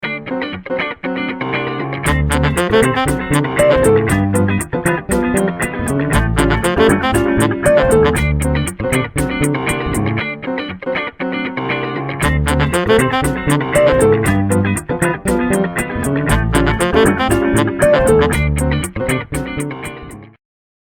саксофон
фанк , фортепиано
джаз